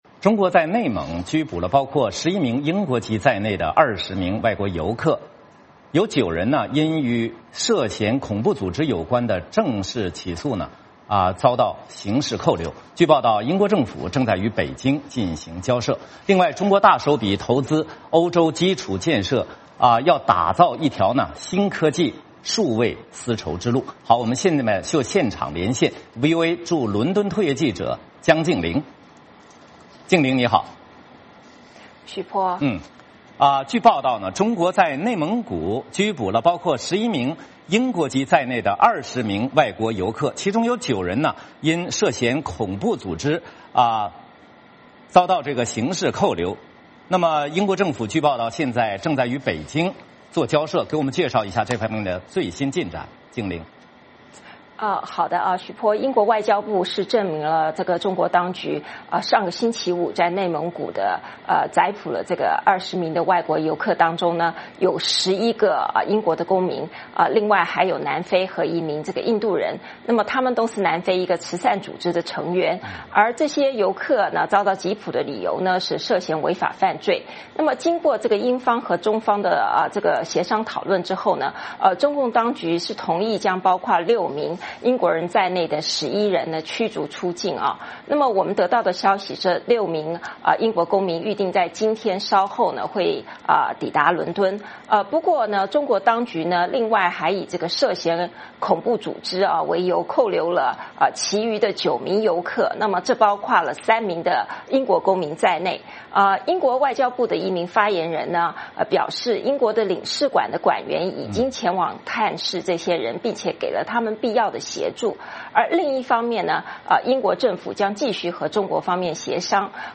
VOA连线：英国政府就中国逮捕外籍人士与北京交涉